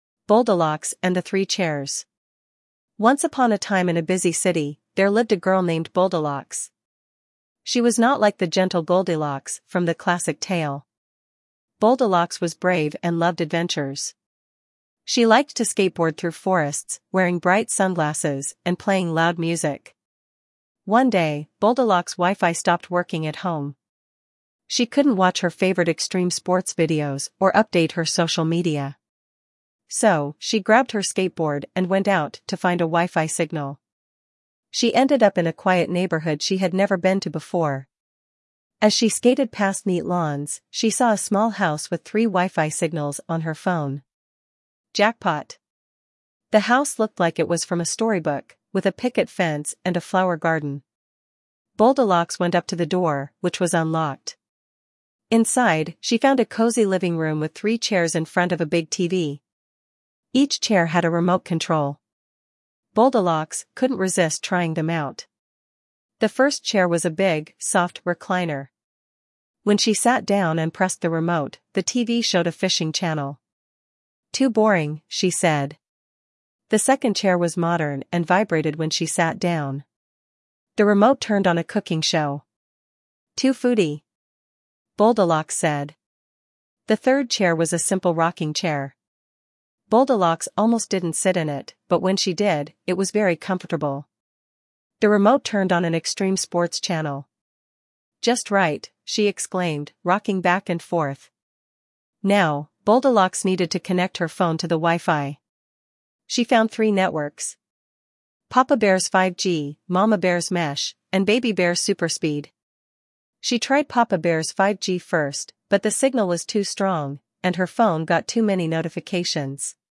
More Audio Books